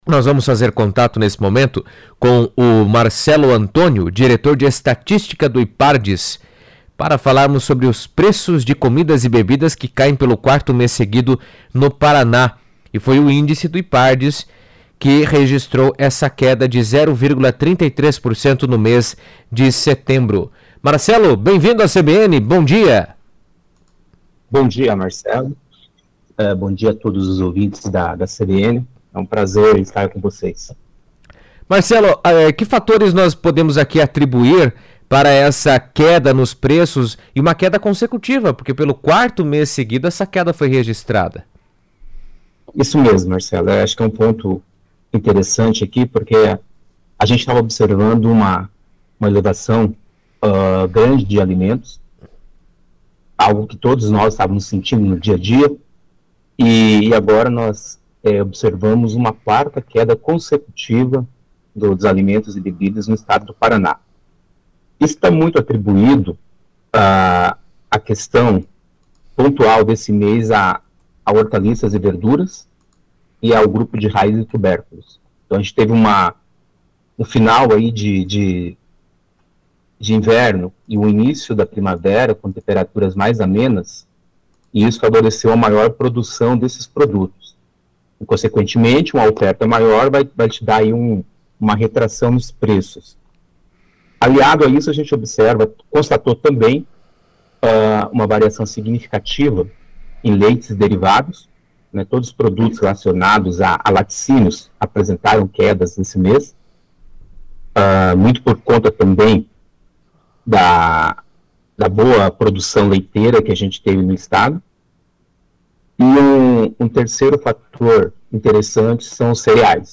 falou na CBN sobre o tema